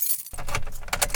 cars / key1.ogg
key1.ogg